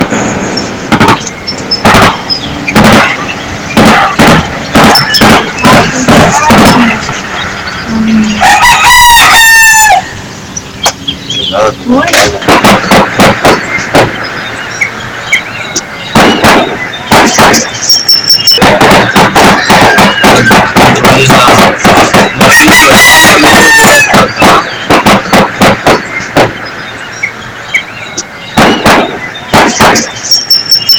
Según informes preliminares, la balacera y los estruendos de detonaciones se prolongaron por más de 15 minutos.
Balazon-apatzingan-.mp3